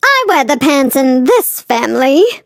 flea_lead_vo_03.ogg